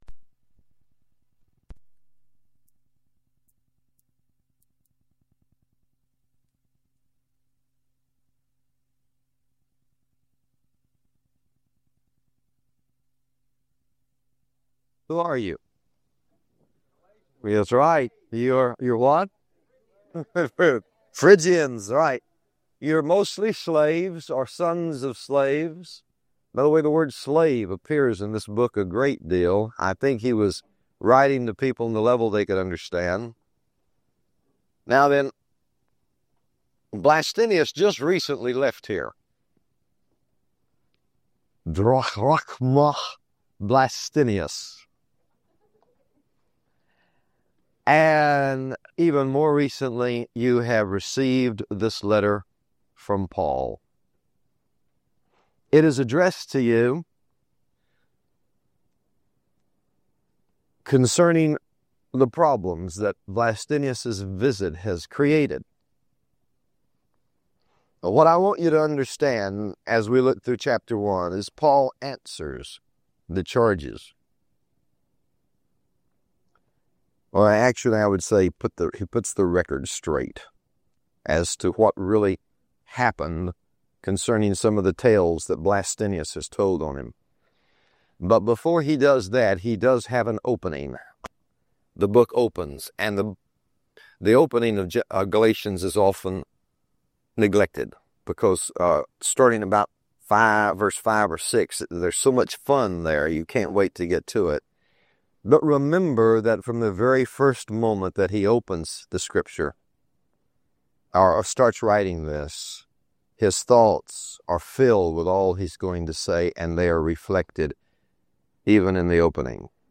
The Christian life is not a code you must keep, but a relationship you already have. Many believers are unknowingly trapped in the “greatest tragedy of all”: striving to obtain by personal merit what Christ already gave them. In this profound message